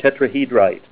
Say TETRAHEDRITE Help on Synonym: Synonym: ICSD 62116   PDF 42-561